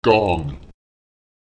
Lautsprecher gán [gaùN] wenig, etwas